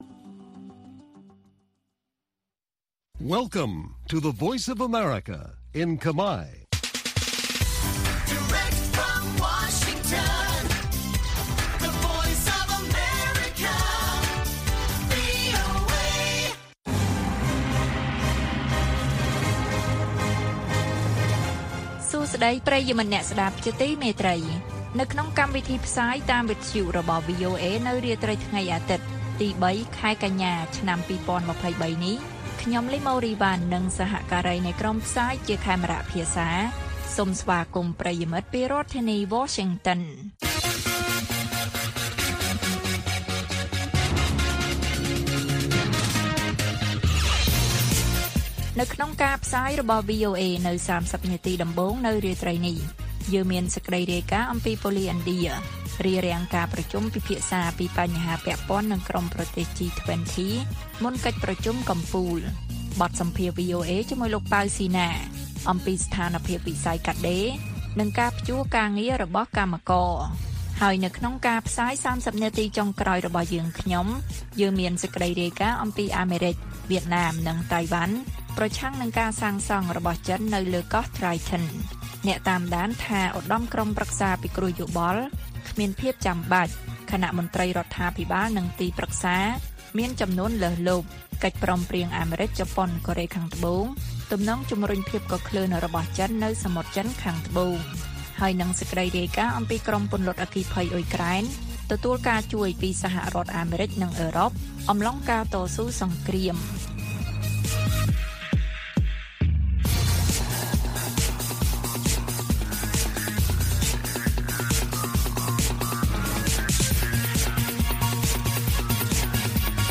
ព័ត៌មាននៅថ្ងៃនេះមានដូចជា ប៉ូលិសឥណ្ឌារារាំងការប្រជុំពិភាក្សាពីបញ្ហាពាក់ព័ន្ធនឹងក្រុមប្រទេស G-20 មុនកិច្ចប្រជុំកំពូល។ បទសម្ភាសន៍ VOA៖ ស្ថានភាពវិស័យកាត់ដេរ និងការព្យួរការងាររបស់កម្មករ។ អាមេរិក វៀតណាម និងតៃវ៉ាន់ប្រឆាំងនឹងការសាងសង់របស់ចិននៅលើកោះ Triton និងព័ត៌មានផ្សេងទៀត៕